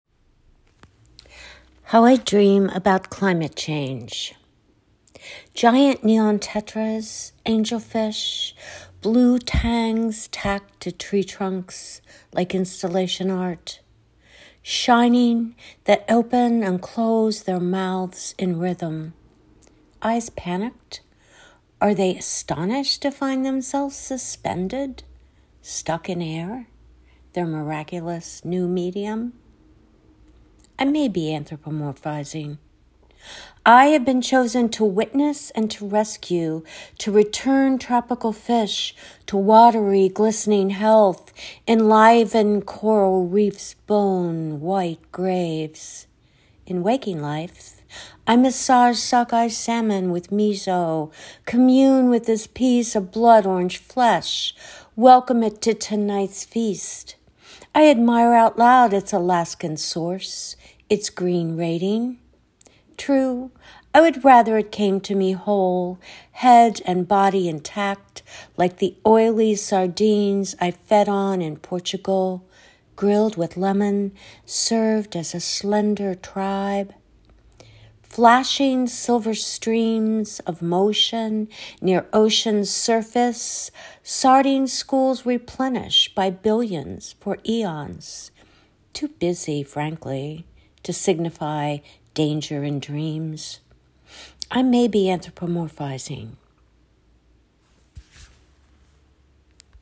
Or you can let them read to you!